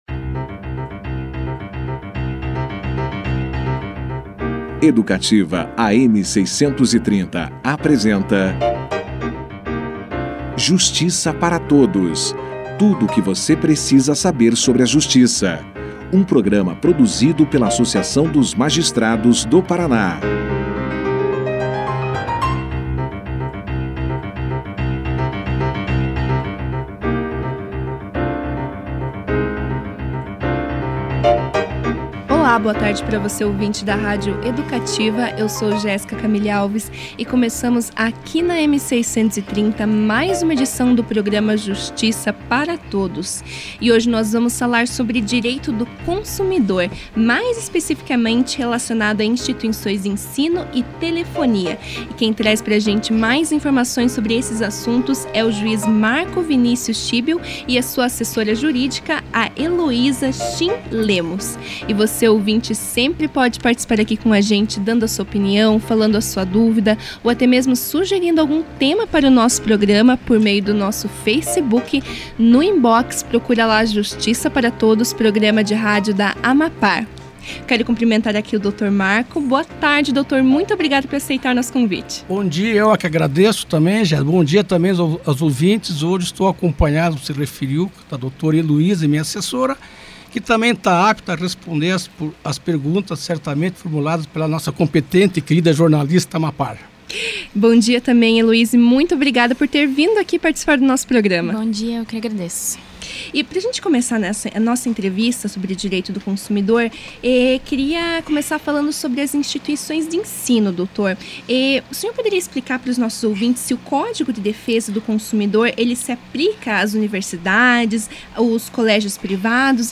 O programa Justiça para Todos recebeu o magistrado e sua assessora nos estúdios da rádio Educativa, AM 630 para falar sobre Direito do consumidor.